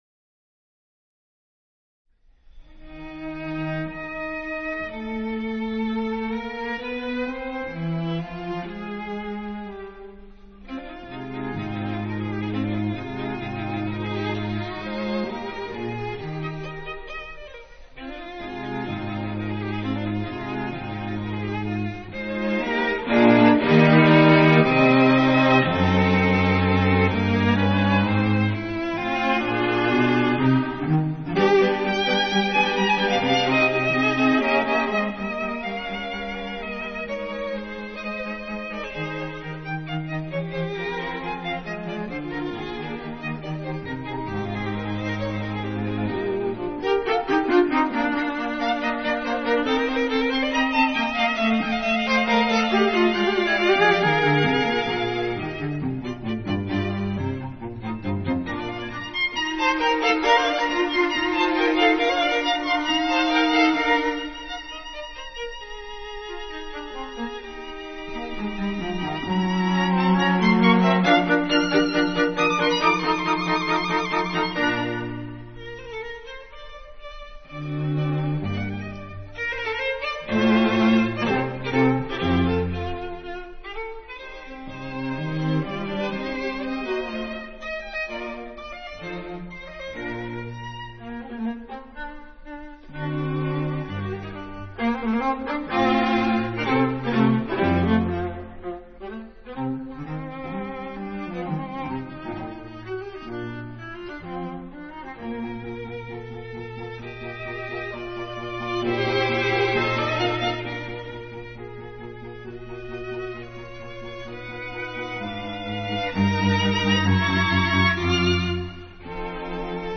String Quartet in E flat major
Allegro ma non troppo